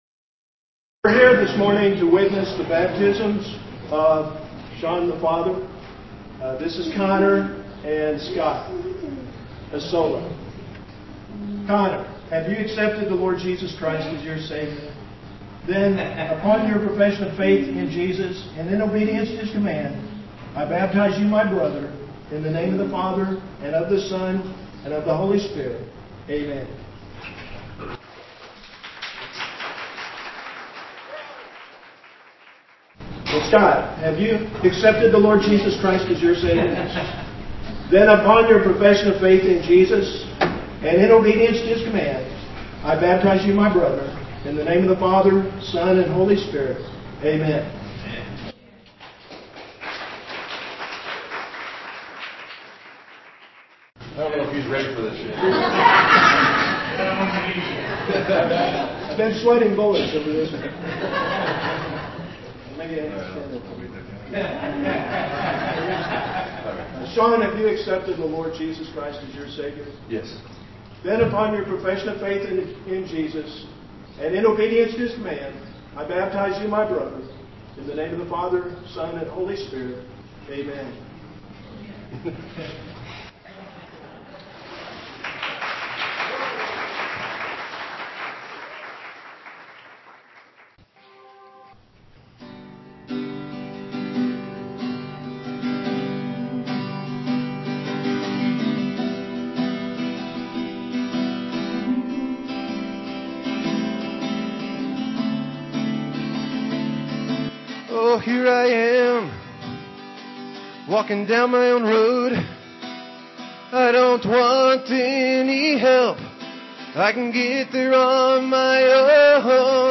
guitar and vocal solo
Piano-organ duet
Children Camp report